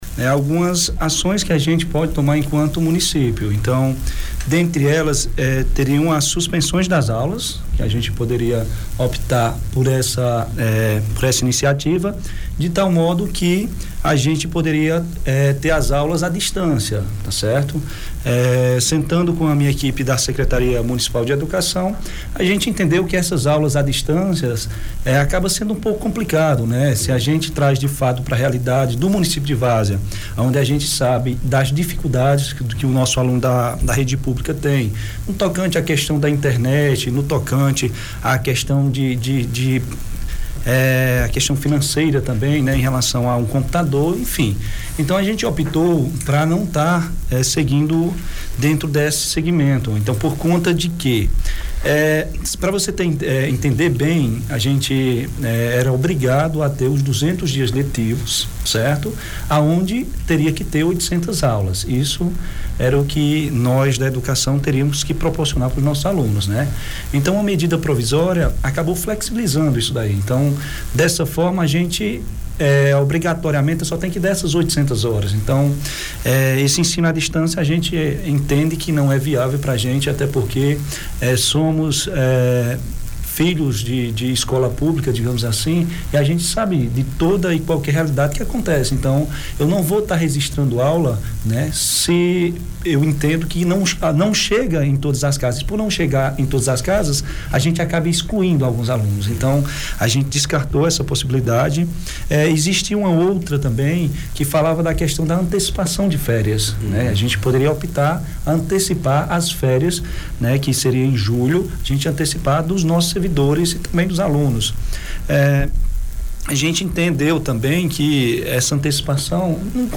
Fernandes esteve ao vivo no Grande Jornal da Cultura e ainda apresentou outras ações: